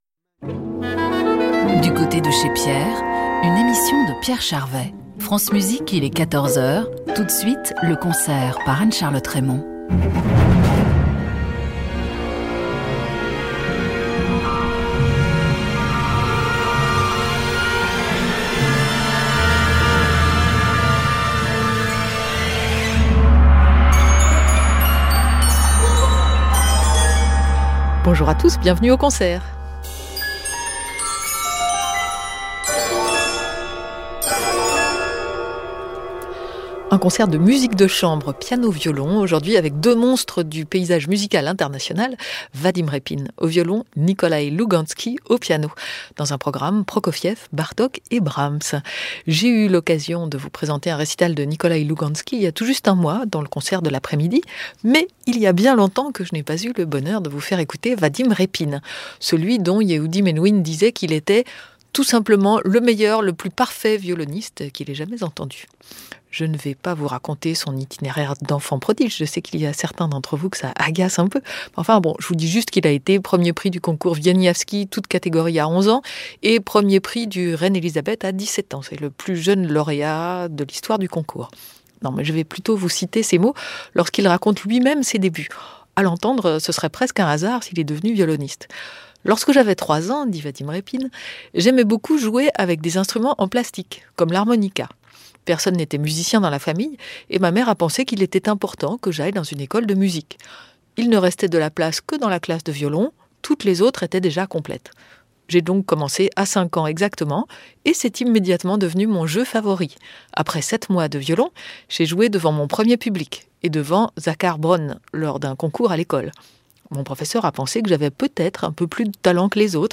Vadim Repin - Nikolai Lugansky In Recital - 2013 - recorded at Theatre Champs-Elysée - March 22, 2013 - Radio France Musique - Past Daily Mid-Week Concert.
Russian-Belgian violinist Vadim Repin along with Russian Pianist Nikolai Lugansky in recital from Champs-Elysée in Paris, recorded on March 22, 2013 by Radio France Musique.
A nicely balanced concert featuring the following:
Sonata for violin and piano